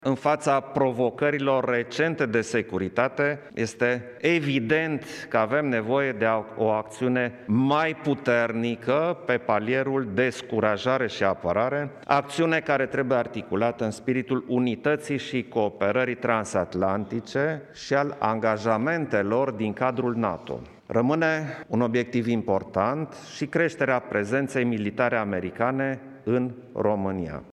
Șeful statului a prezentat în fața ambasadorilor acreditați la București prioritățile de politică externă ale României: